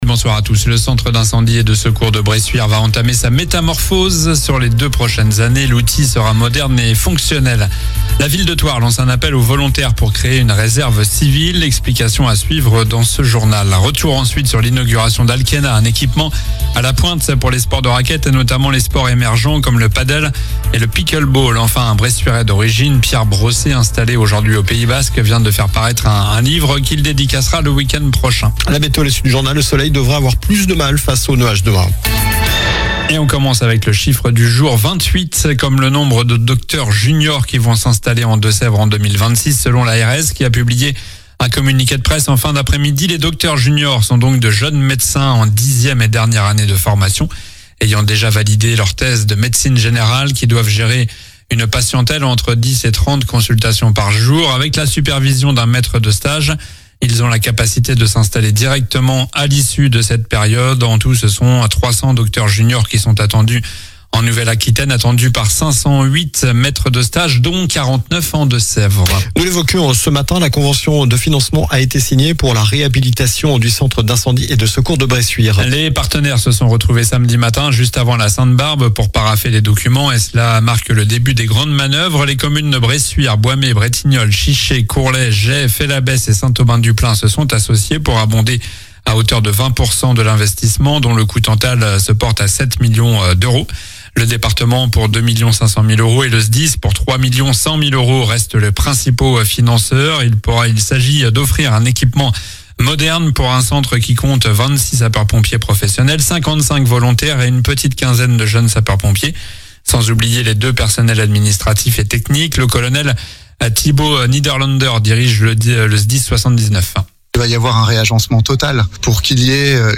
Journal du lundi 15 décembre (soir)